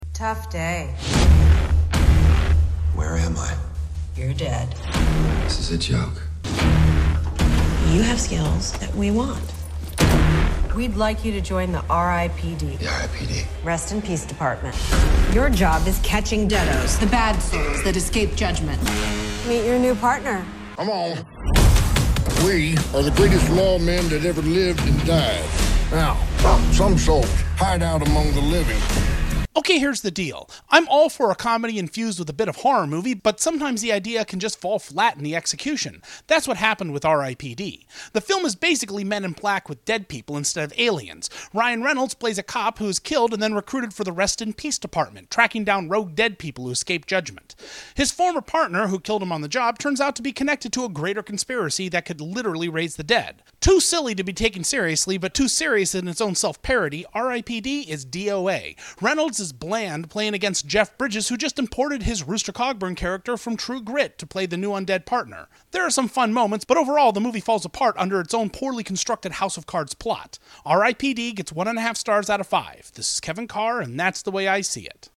Movie Review